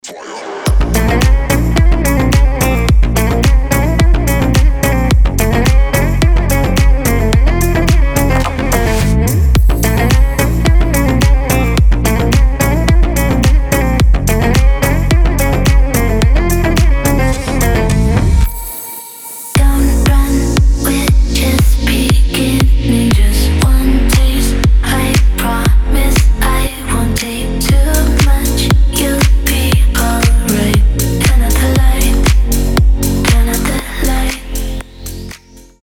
• Качество: 320, Stereo
красивые
deep house
женский голос
восточные
Шикарно звучит этот дип